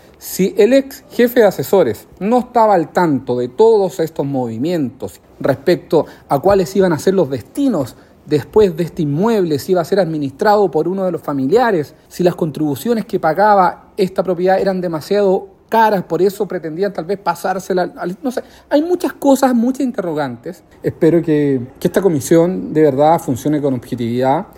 El diputado RN, Jorge Durán, acusó trabas del oficialismo para realizar citaciones a la comisión.